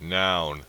Ääntäminen
UK : IPA : /naʊn/ US : IPA : /naʊn/